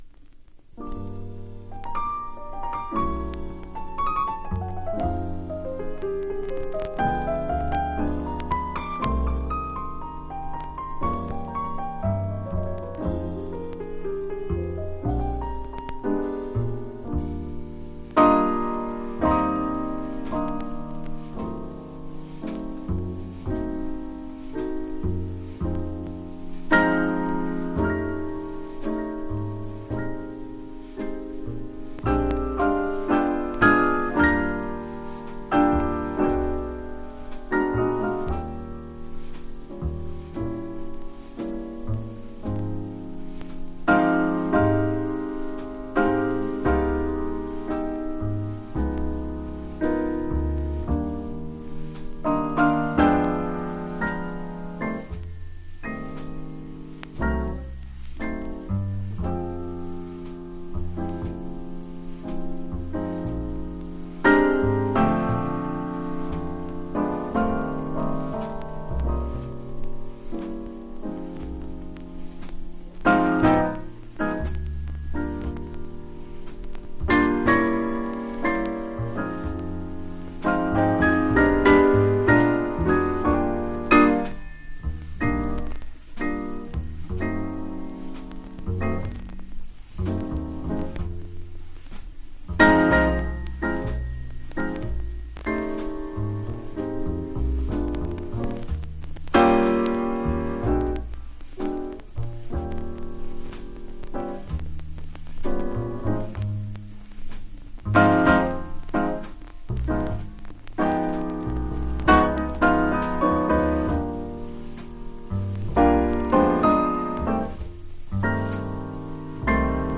（プレスによりチリ、プチ音ある曲あり）※曲名をクリックすると試聴できます。